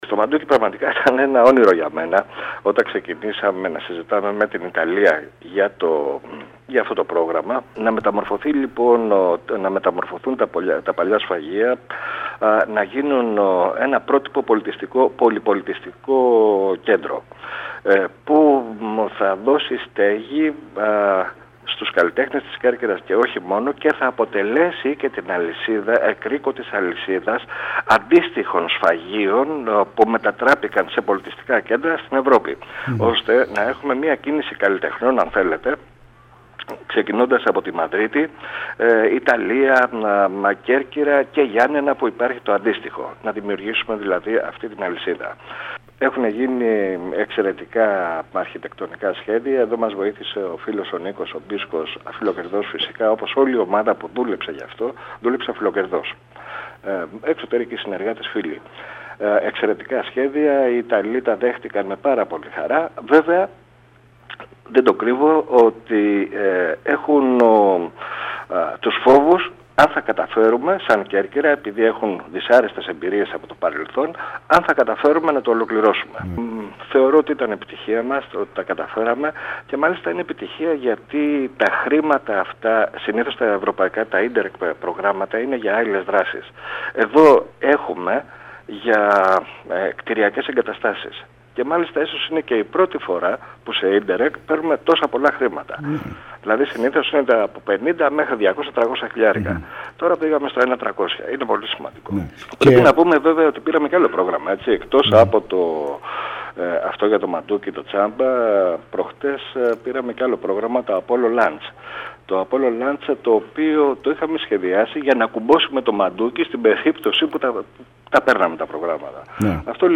Σε  πολυ-πολιτισμικό κέντρο ευελπιστεί να μετατρέψει τα παλιά σφαγεία στο Μαντούκι ο Δήμος Κέρκυρας, στο πλαίσιο χρηματοδότησης ευρωπαϊκών προγραμμάτων που αφορούν την πολιτισμική αναβάθμιση αστικών περιοχών.  Όπως ανέφερε ο Αντιδήμαρχος Τουρισμού Βασίλης Καββαδίας μιλώντας στην ΕΡΤ Κέρκυρας, «εκτός του κέντρου της Πόλης που άμεσα ξεκινά να μεταμορφώνεται από τις παρεμβάσεις στα ιστορικά κτίρια και τις αναπλάσεις εξωτερικών χώρων που χρηματοδοτούνται από την Ολοκληρωμένη Χωρική Επένδυση με 71,4 εκατομμύρια ευρώ,  εγκρίθηκε η πρόταση του Δήμου ύψους 1,4 εκ ευρώ που αφορά την μετατροπή των παλαιών σφαγείων σε πολύ-πολιτιστικό κέντρο με τη συνεργασία άλλων ευρωπαϊκών πόλεων.